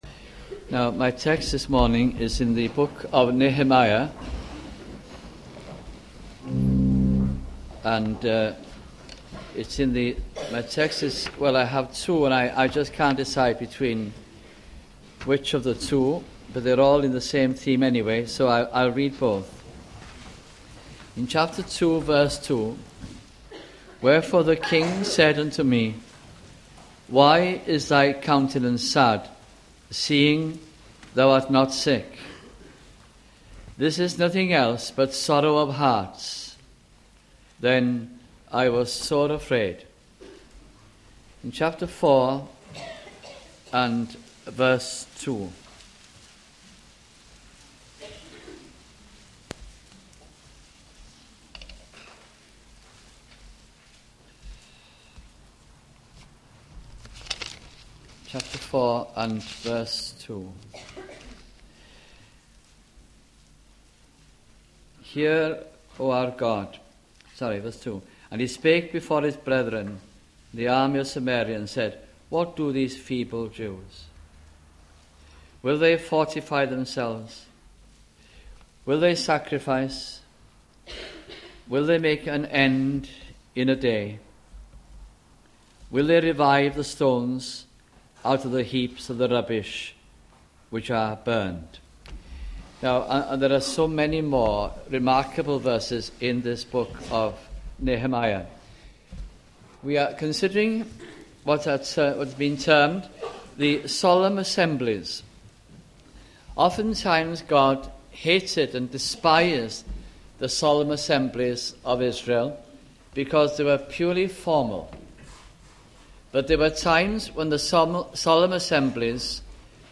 » Solemn Assemblies Series 1992-93 » sunday morning messages